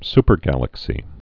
(spər-gălək-sē)